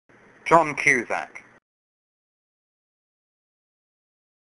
來！讓小丸子陪你邊聊明星，邊練發音，當個真正的追星族！